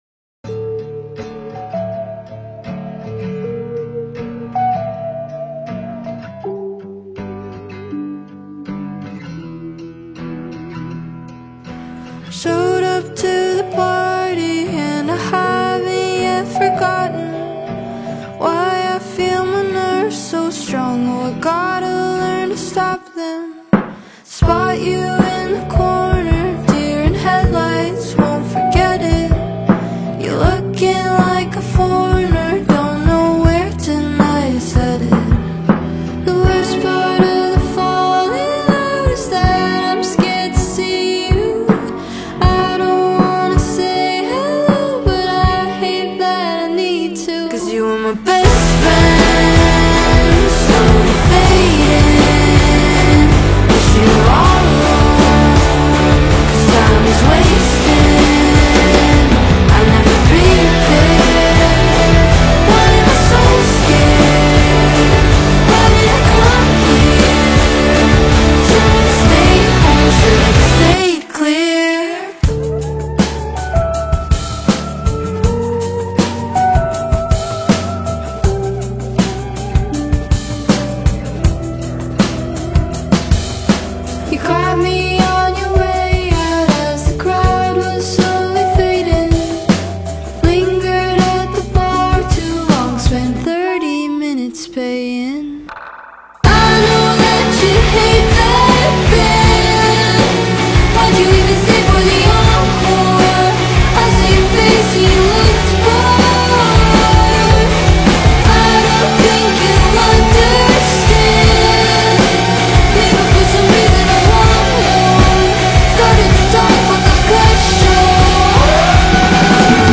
Reputable American singer and songwriter
has a sensational beat